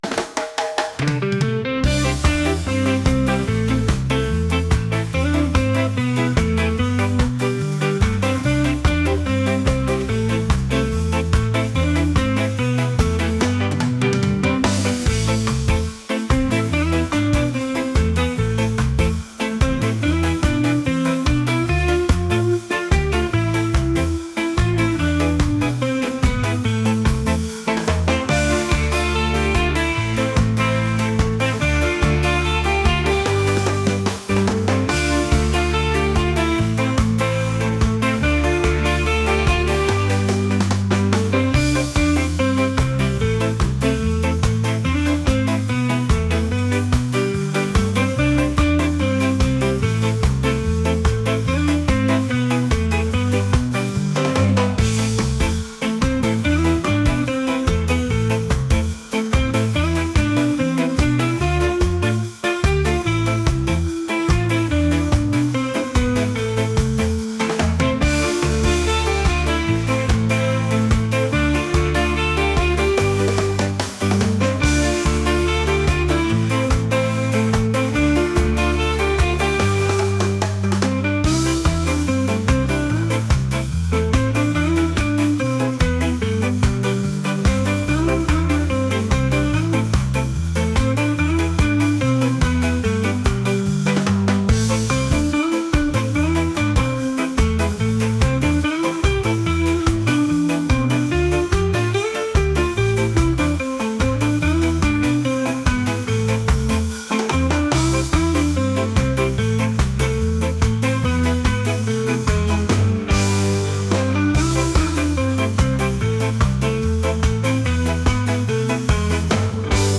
reggae | rock